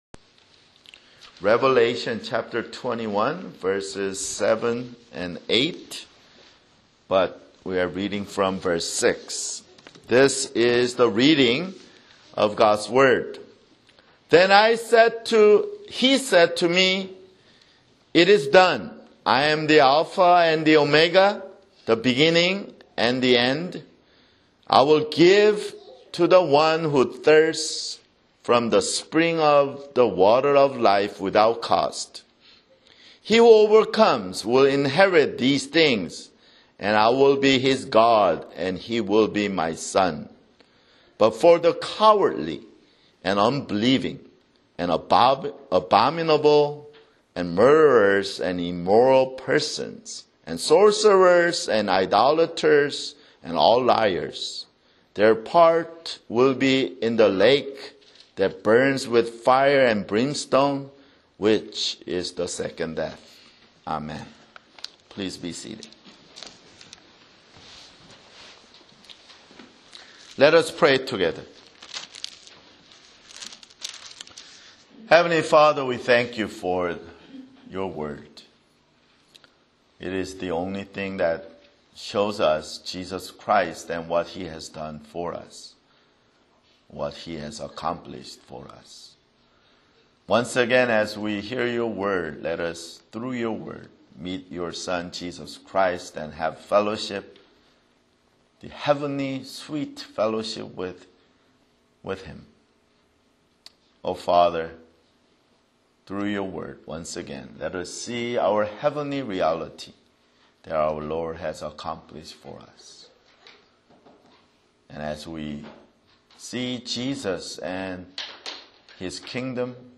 [Sermon] Revelation (86)